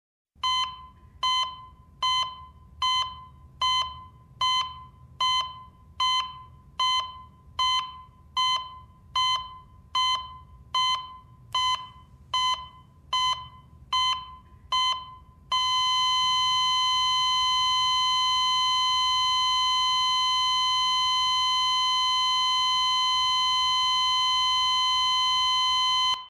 Звуки кардиографа
Звук, в котором сердце сперва билось, а затем замерло (писк кардиографа)